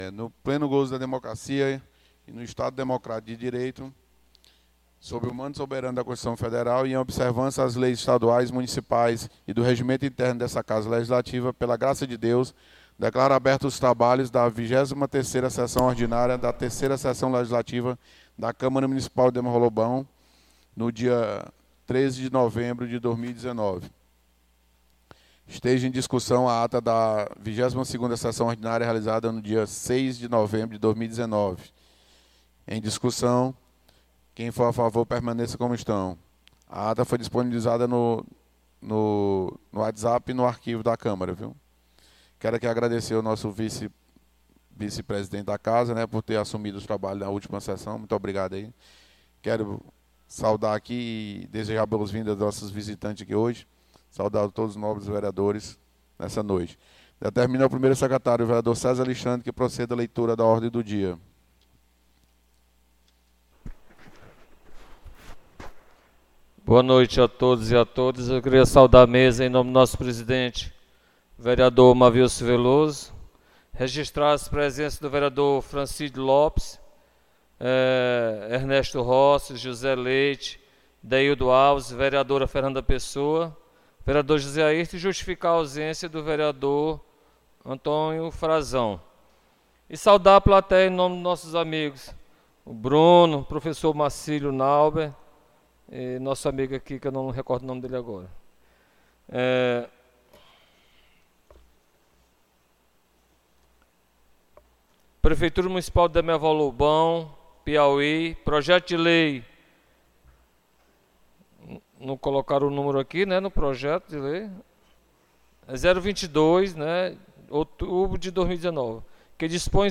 23ª Sessão Ordinária 13 de Novembro